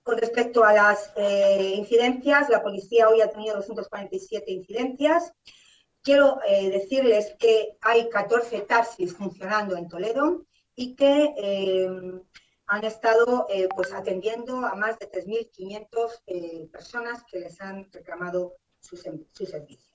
La alcaldesa de Toledo, Milagros Tolón, ha comparecido este martes para dar a conocer las últimas informaciones de las que dispone sobre el dispositivo que trabaja para paliar los efectos de la borrasca Filomena a su paso por la ciudad así como el estado de los diferentes servicios e infraestructuras municipales.